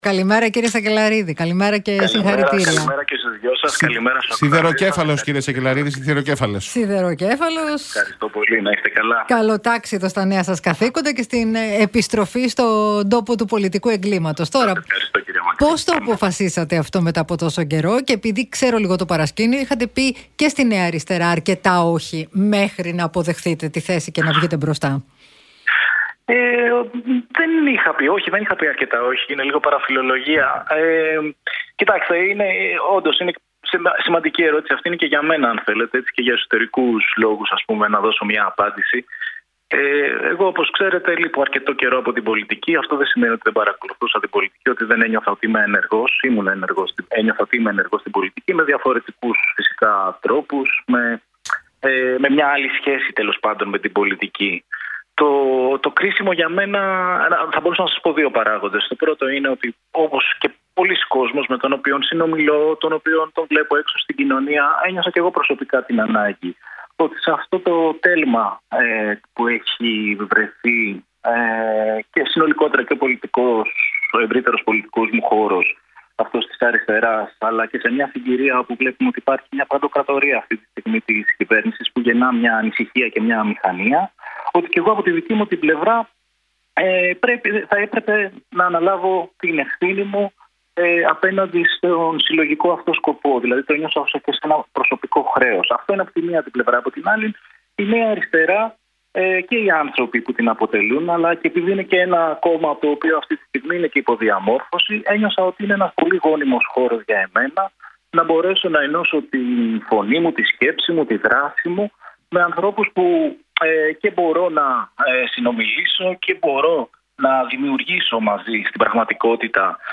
Την πρώτη του συνέντευξη μετά την επιστροφή του στην ενεργό πολιτική με τη Νέα Αριστερά παραχώρησε ο Γαβριήλ Σακελλαρίδης μιλώντας στον Realfm 97,8